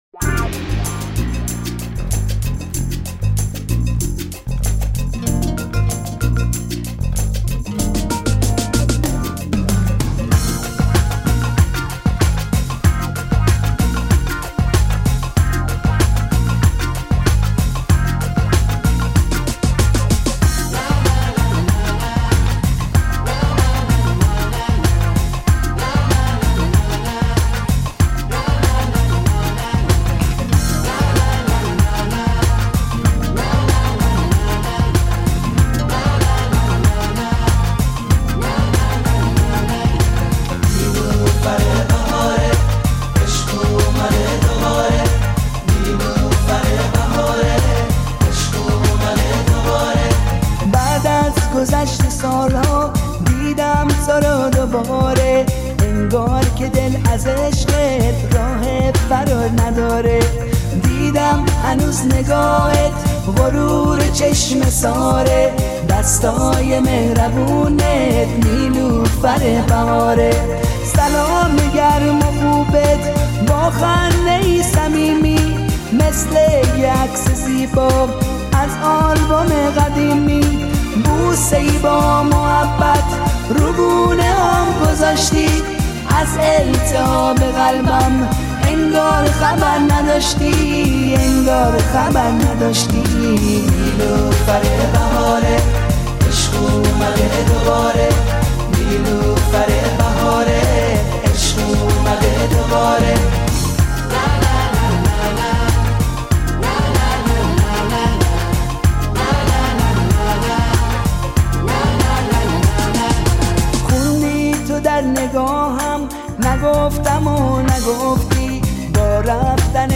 Музыка / Иранские / Поп / Ретро / Прочее / Альбом